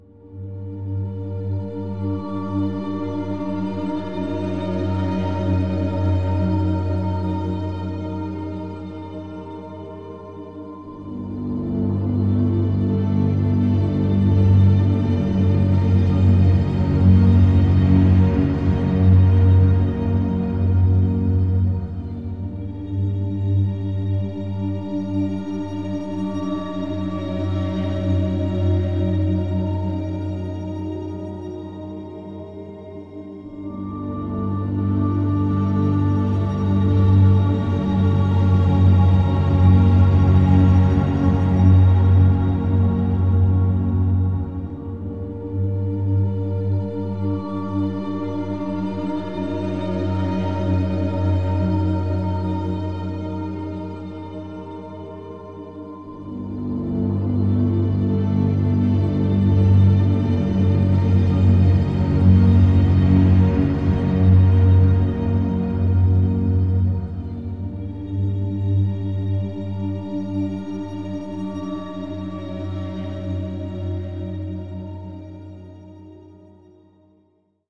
Ambiance
massacre_scene_loop.mp3